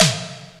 EMX SNR 11.wav